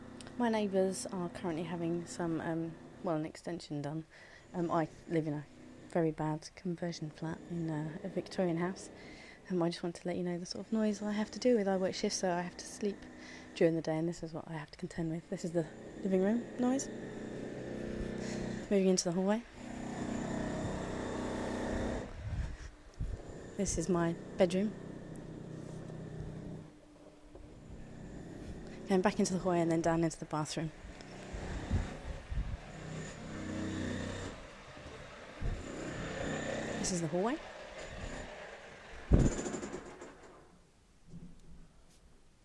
How can I sleep through this noise?